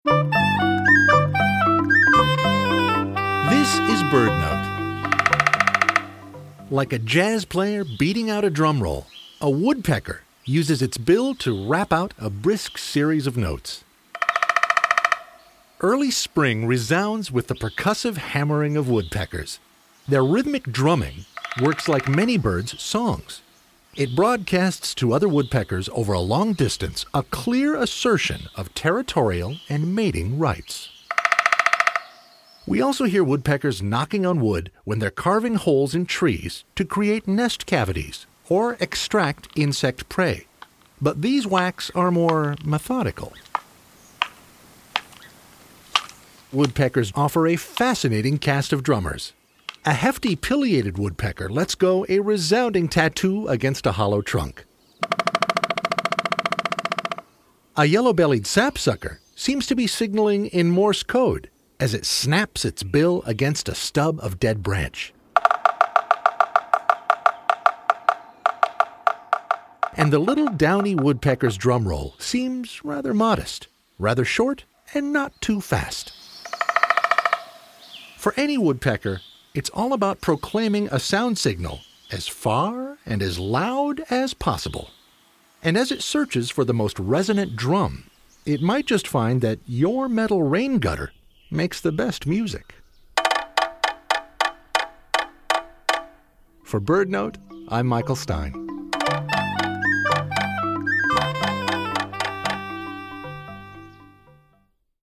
Like a jazz player beating out a drum roll, a woodpecker uses its bill to rap out a brisk series of notes. Early spring resounds with the percussive hammering of woodpeckers. Their rhythmic drumming says to other woodpeckers, “This is my territory!”